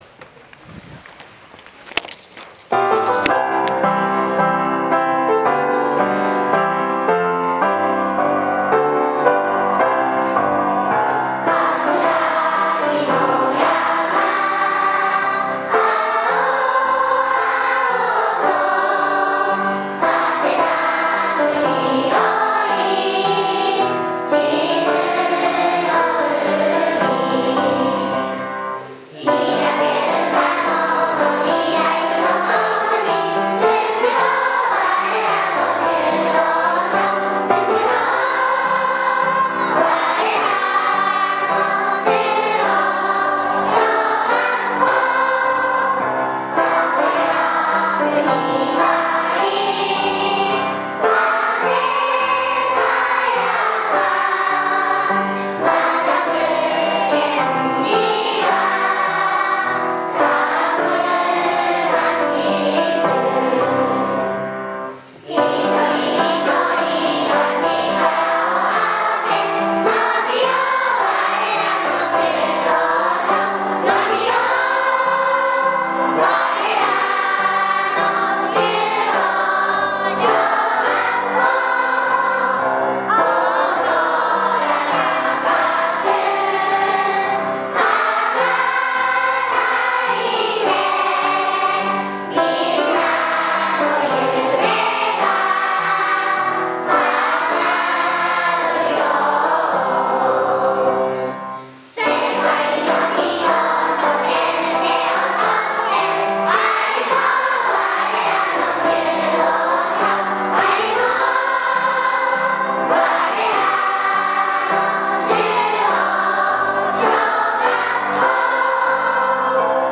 校歌|泉佐野市